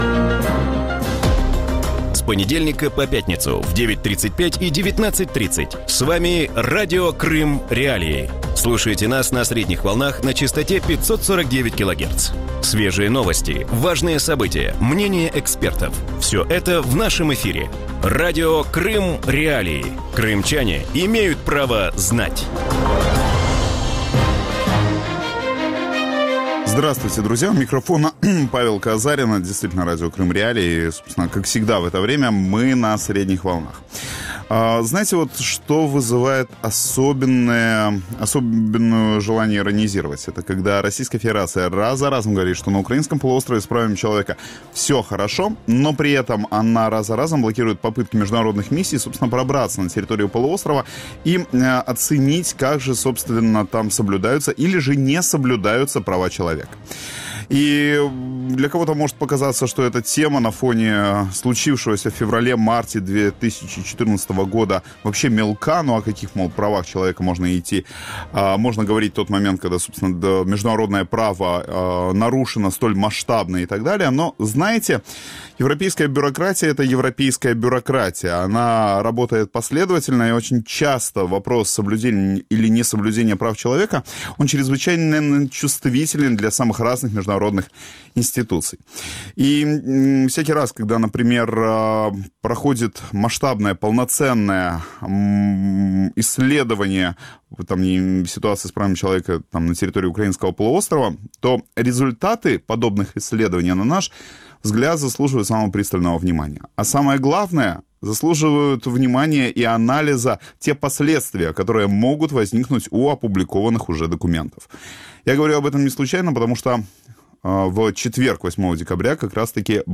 В вечернем эфире Радио Крым.Реалии говорят о том, что происходит с правами человека на полуострове, и как эту ситуацию оценивают международные организации. О чем идет речь в последнем отчете мониторинговой миссии ООН в Крыму, есть ли доступ к информации у международных правозащитников и как отчеты международных организаций могут помочь Крыму?